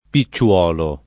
pi©©U0lo]) s. m. («gambo») — es. con acc. scr.: spiccando con due dita i picciòli delle ciliege [Spikk#ndo kon due d&ta i pi©©0li delle ©ilL$Je] (Calvino)